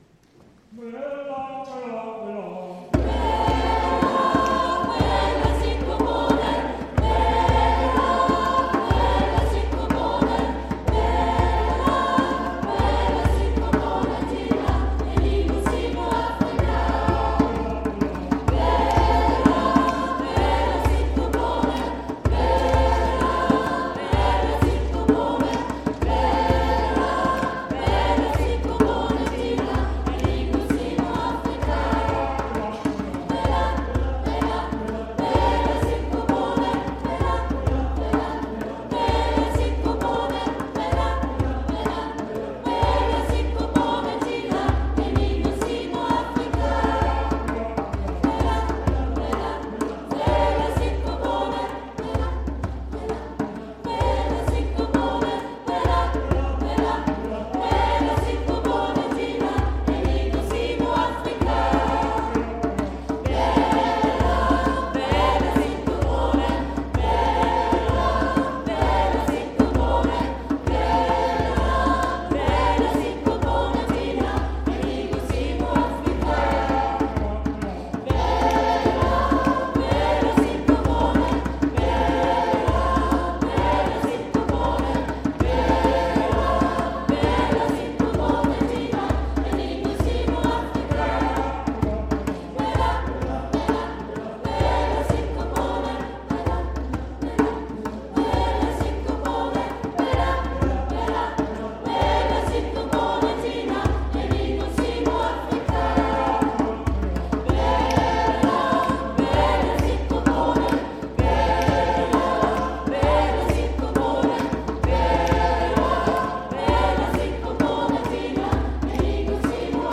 Unser Chor begleitete diese Messe mit neuen afrikanischen Gesängen die den einen oder anderen Kirchenbesucher zum "mitshaken" animierten.
Die afrikanischen Lieder aus dem Gottesdienst